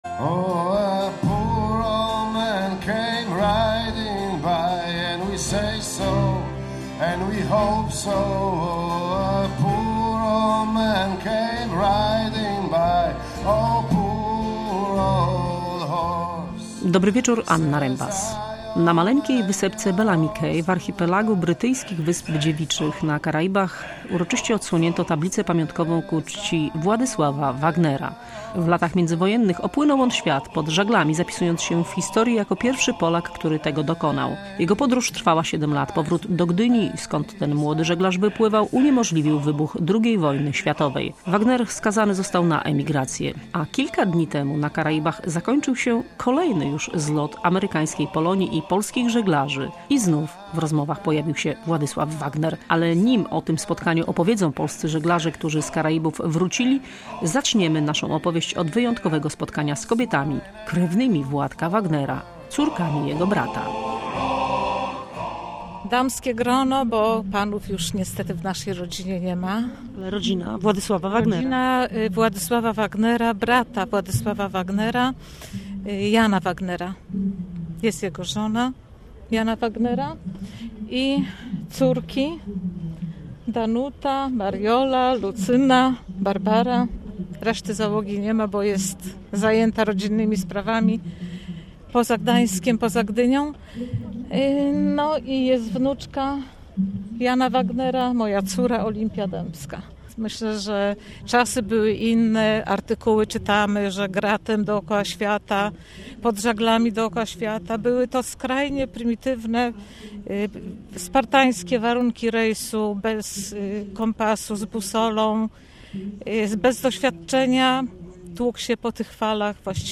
Opowiada o nim rodzina.